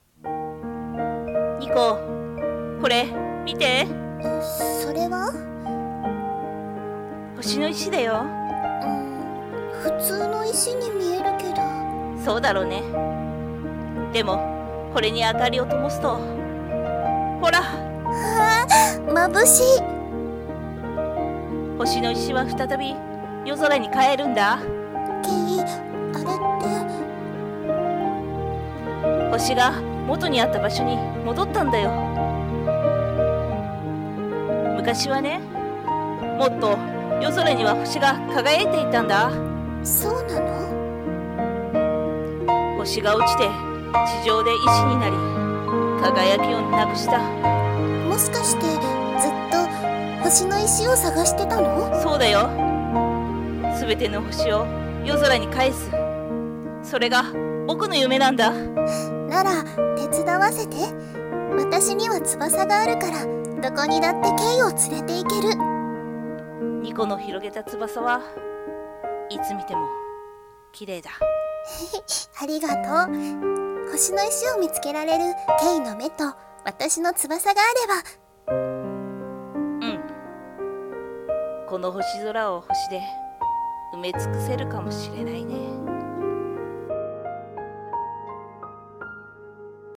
声劇【SKY~星~】※二人声劇